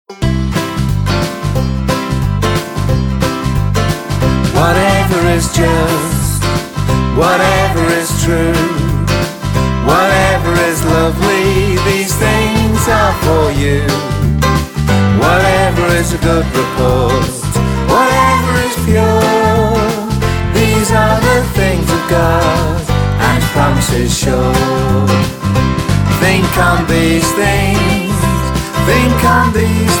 Kinderlieder